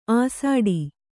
♪ āsāḍi